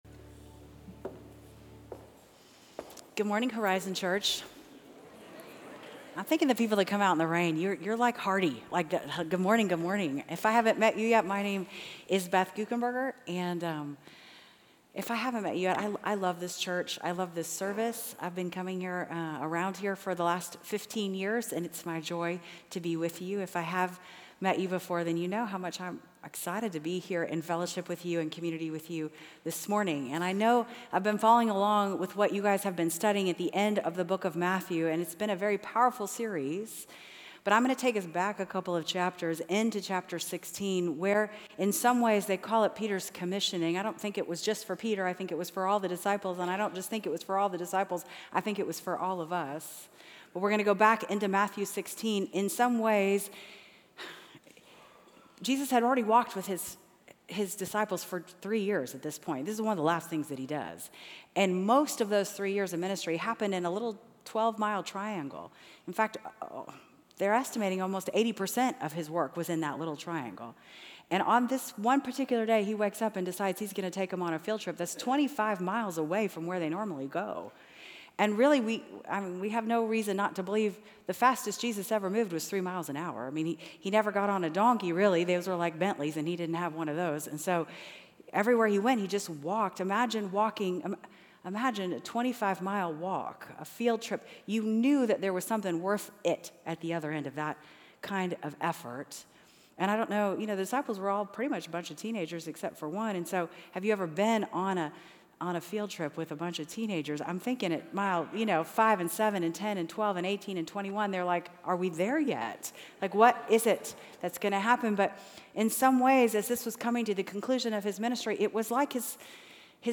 Equipping Service